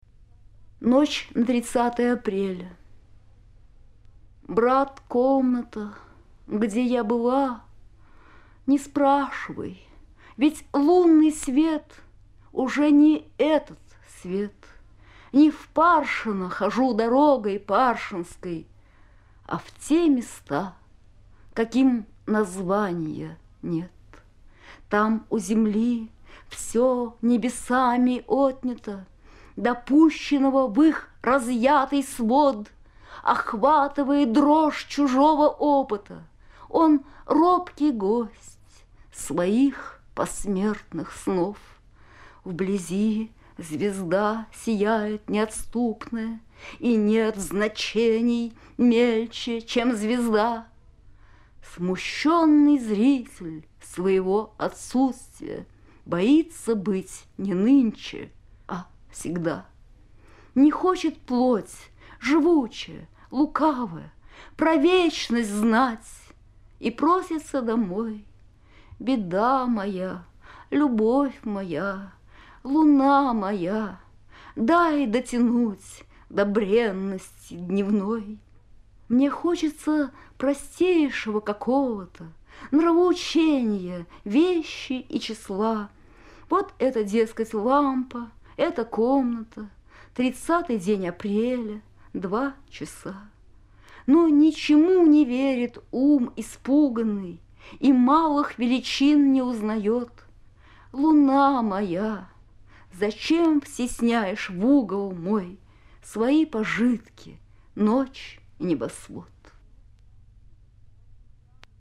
bella-ahmadulina-noch-na-30-aprelya-chitaet-avtor